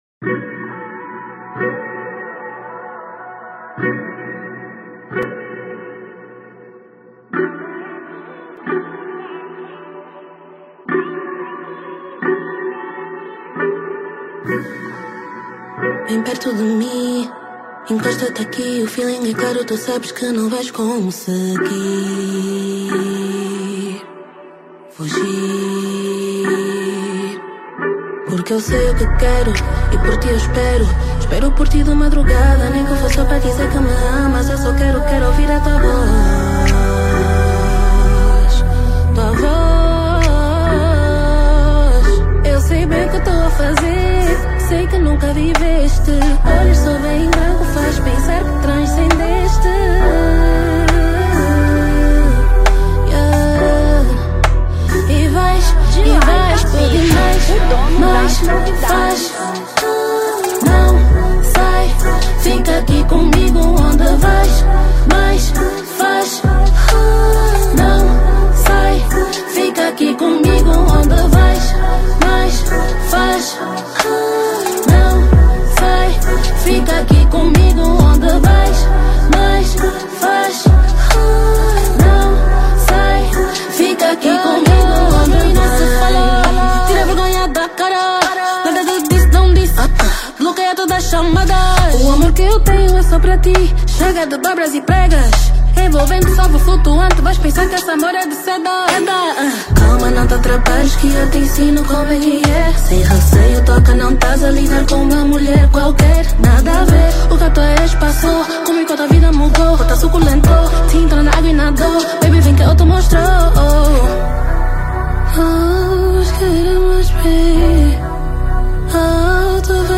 R&B 2025